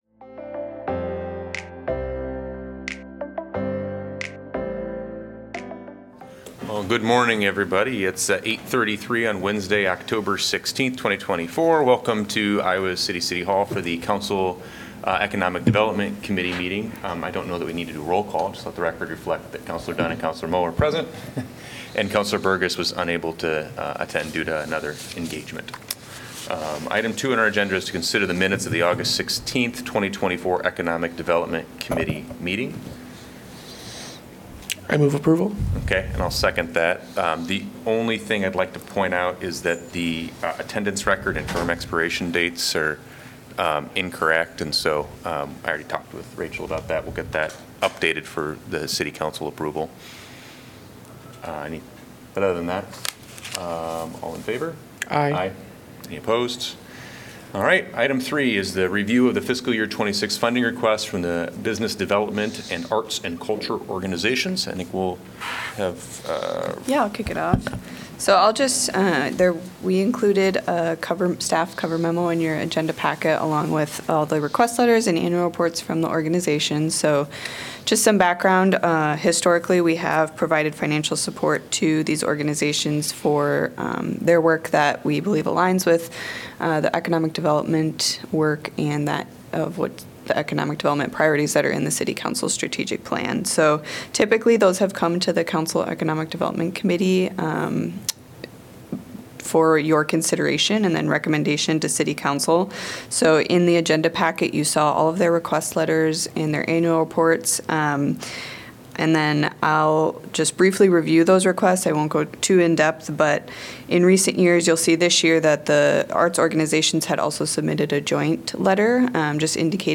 A meeting of the Iowa City City Council's Economic Development Committee.